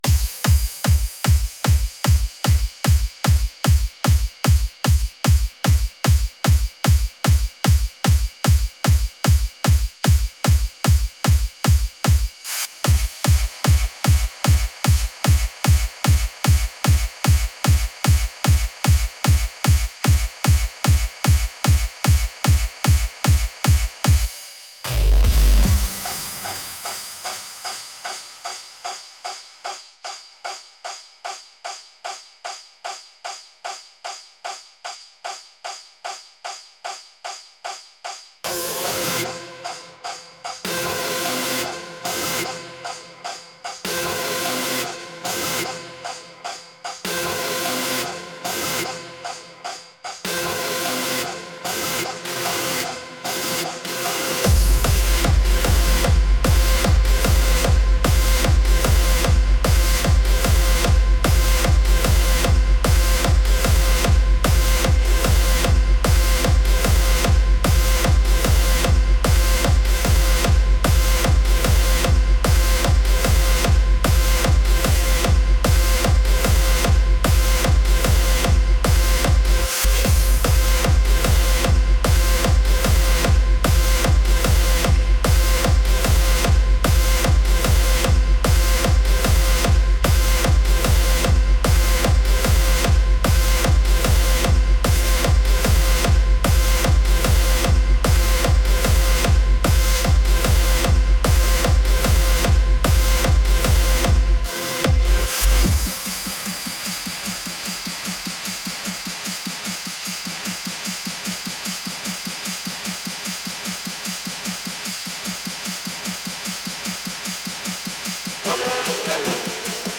energetic | intense